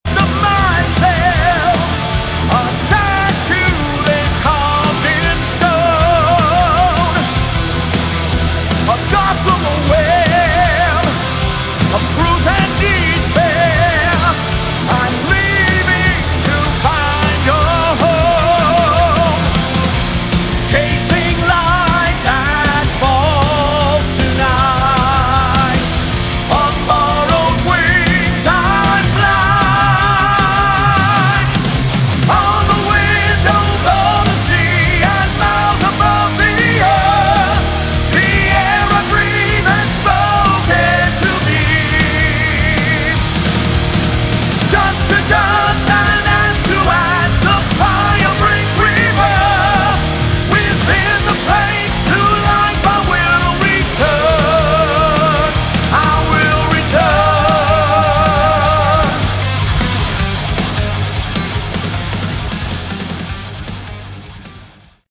Category: Melodic Metal
vocals
guitars
bass
drums
kayboard, piano
synth and orchestral arrangements